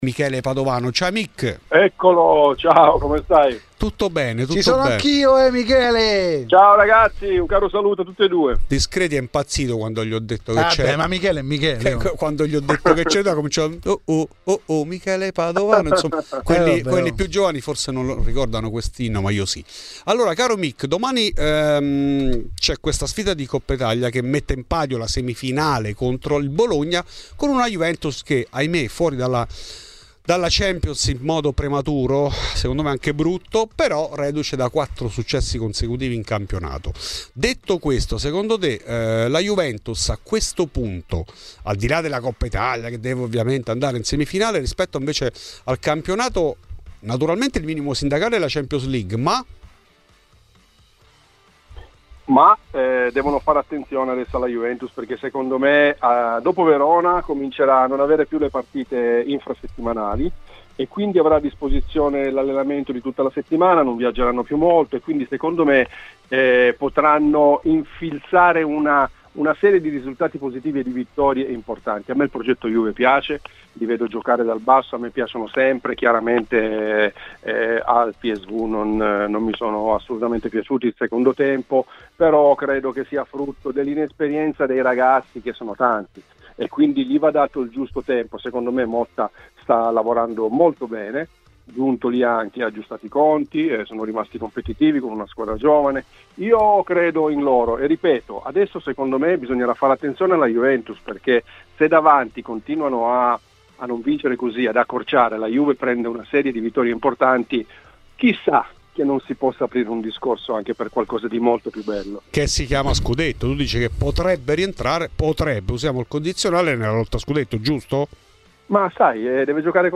Questi e la disparità di trattamento della Giustizia sportiva, sono gli argomenti trattati in ESCLUSIVA a Fuori di Juve dall'ex attaccante bianconero Michele Padovano .